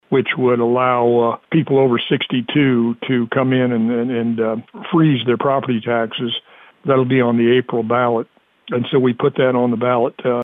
Presiding Commissioner Ed Douglas says they filed the Ballot Question for Senate Bill 190.